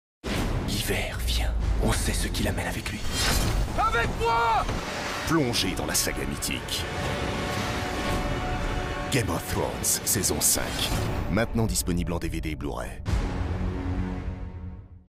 Voix percutante.
Spot DVD de la saison 5 de Game of Thrones.
Pour Game of Thrones, j’ai choisi une voix grave et percutante. Viril, dramatique, convaincant, chaque mot est prononcé pour susciter une véritable sensation blockbuster, pour faire vibrer le public et l’attirer dans cet univers médiéval fantastique.